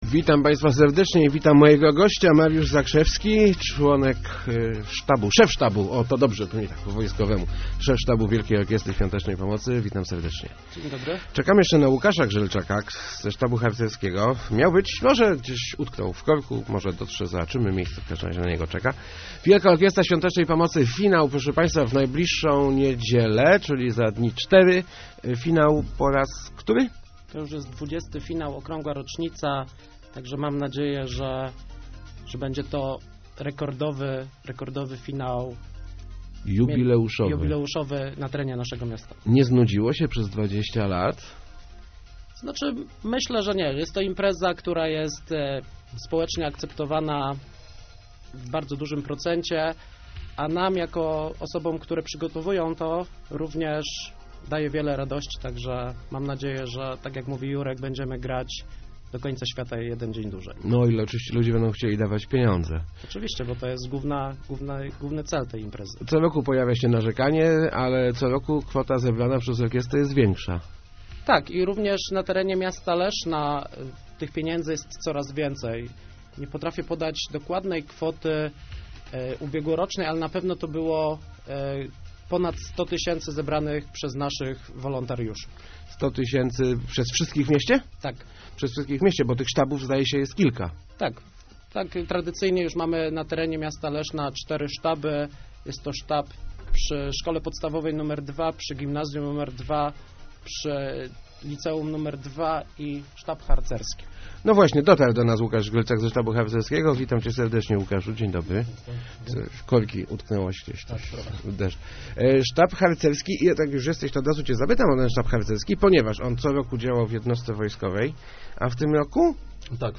Ponad 450 wolontariuszy weźmie w Lesznie udział w XX finale Wielkiej Orkiestry Świątecznej Pomocy. Będzie ona grała od soboty - mówli w Rozmowacg Elki koordynatorzy akcji